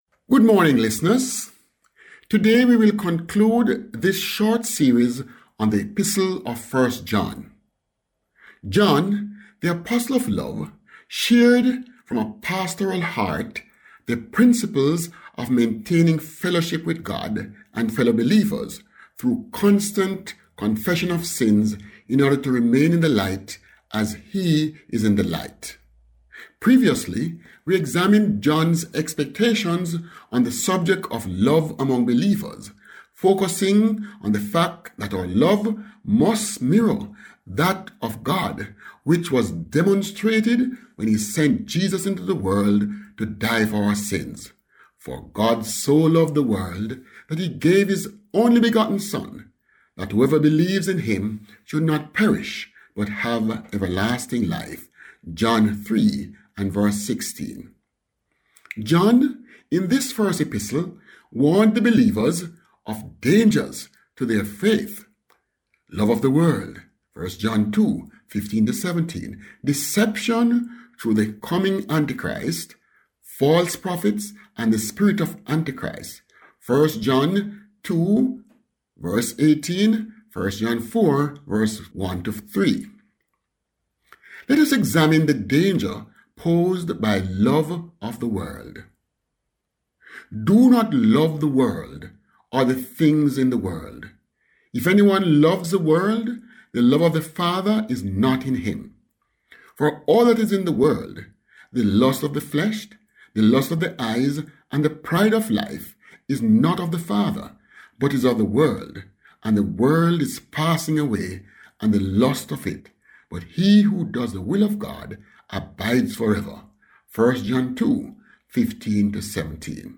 Grace Hour Broadcast